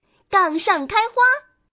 Index of /client/common_mahjong_tianjin/mahjongwuqing/update/1161/res/sfx/changsha/woman/